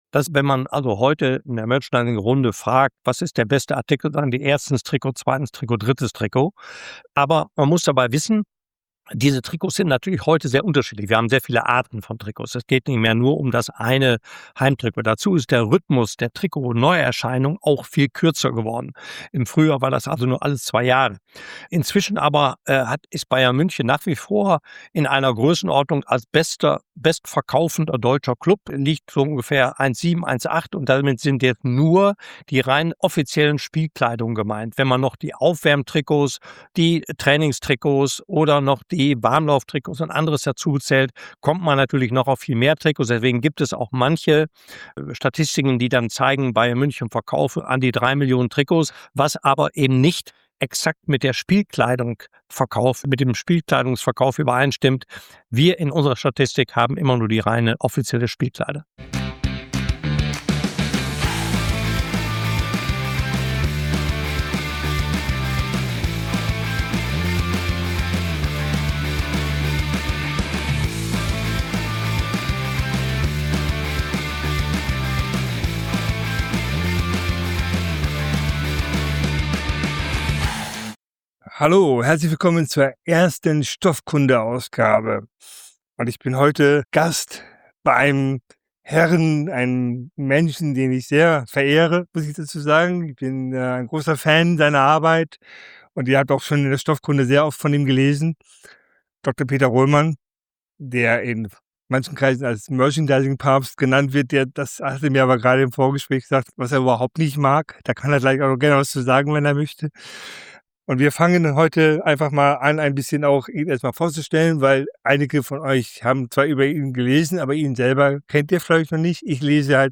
Interviewpartner